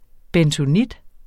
Udtale [ bεntoˈnid ]